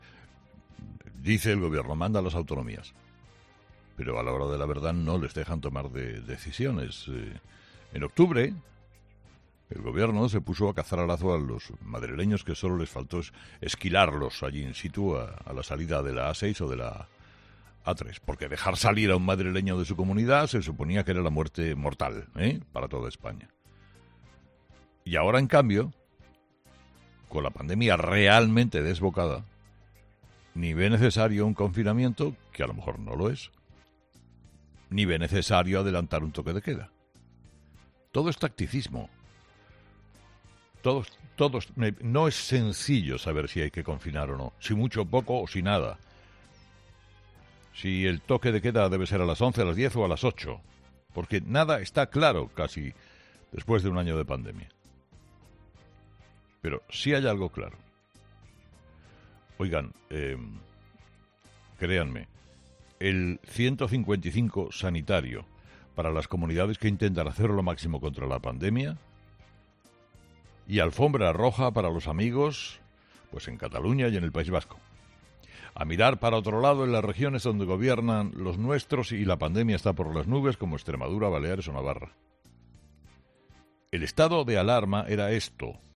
El presentador de 'Herrera en COPE', Carlos Herrera, ha destapado este frío lunes el juego en el que se encuentran Sánchez y Redondo con la gestión de la terca ola de la covid-19. En concreto, Herrera ha querido centrar gran parte de su discurso en la polémica que se ha vivido durante el fin de semana y que ha tenido que ver con el horario en el que las comunidades pueden confinar a sus ciudadanos.